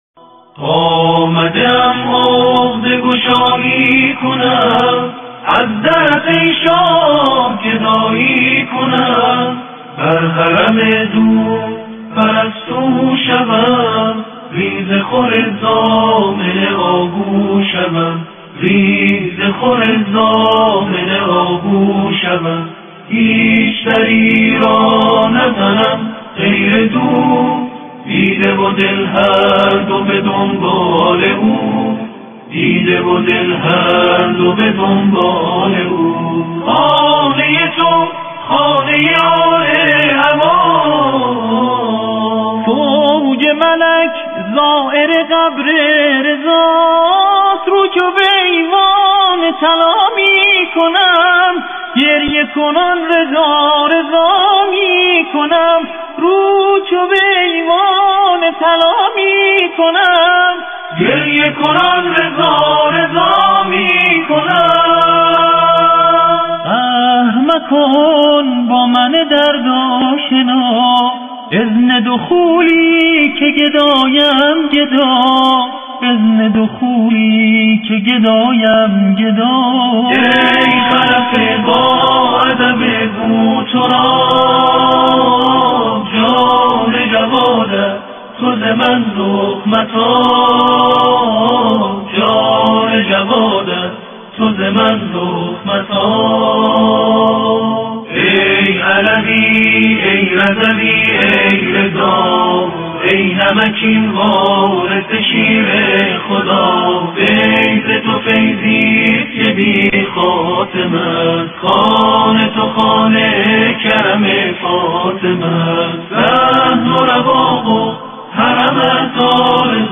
تواشیح
تواشیح-ولادت-امام-رضا.mp3